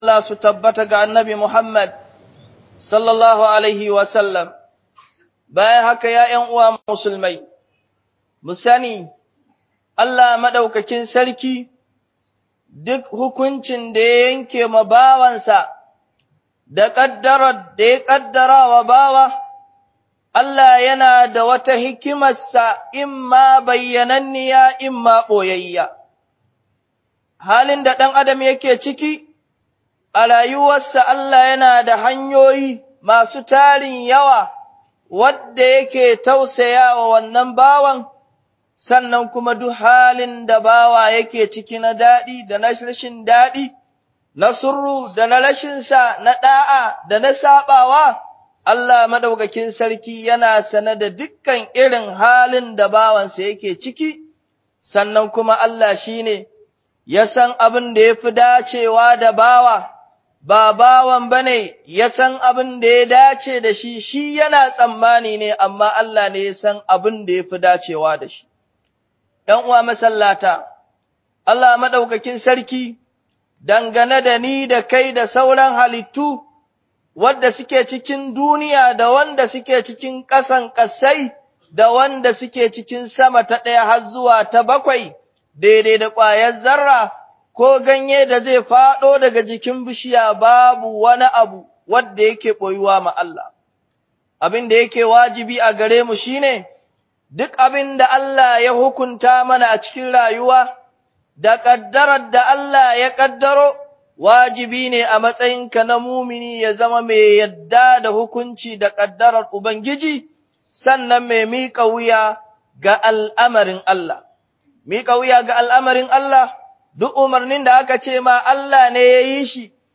BookHUƊUBOBI